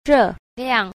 2. 熱量 – rèliàng – nhiệt lượng